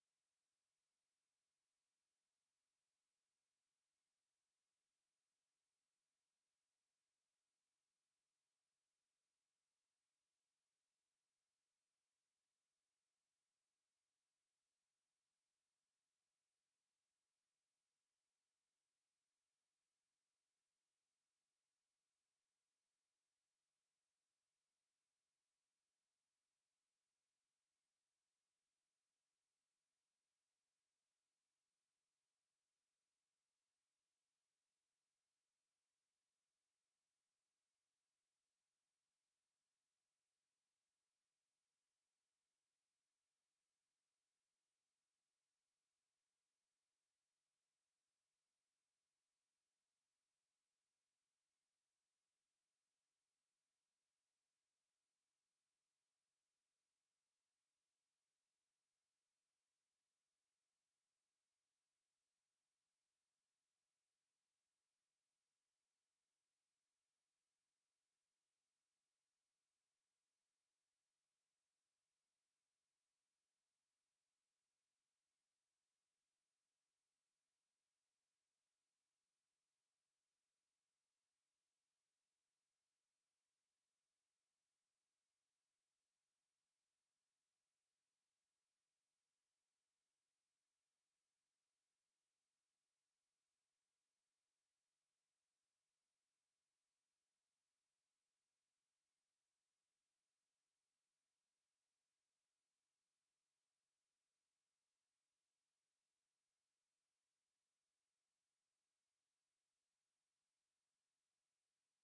Conversation with WALTER HELLER, October 27, 1964
Secret White House Tapes